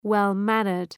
Shkrimi fonetik {,wel’mænərd}